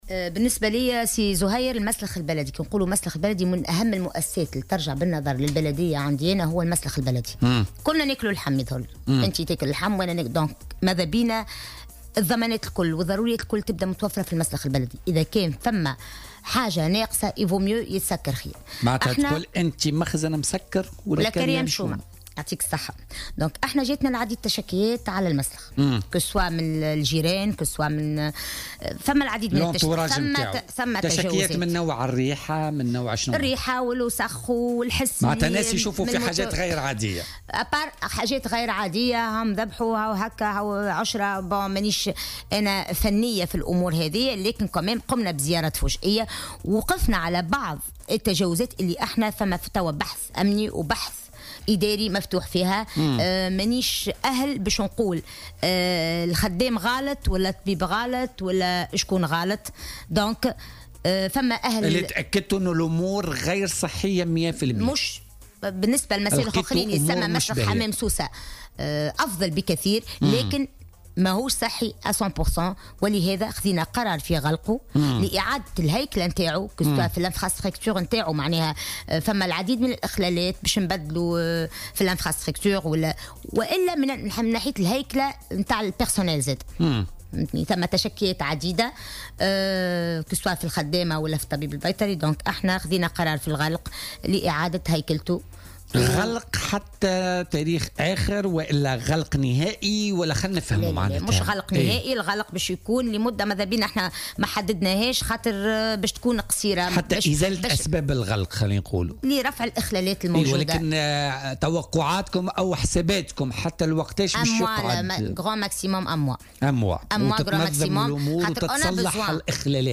ضيفة "بوليتيكا"